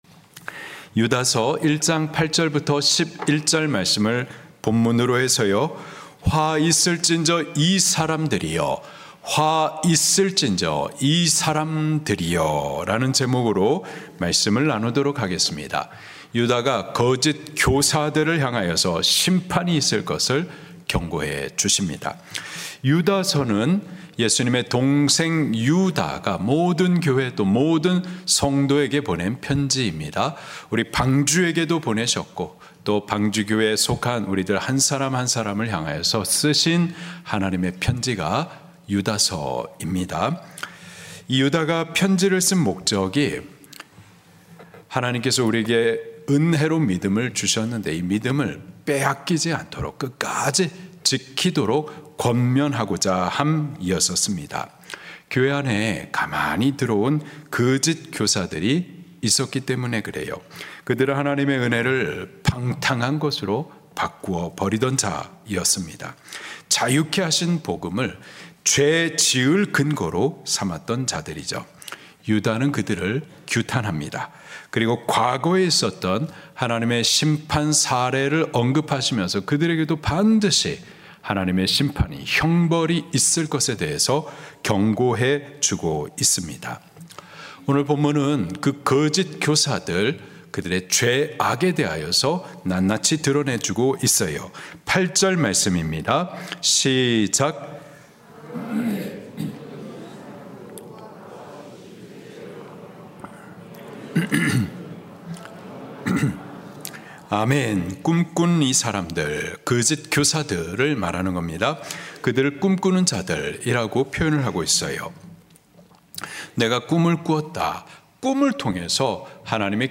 설교
주일예배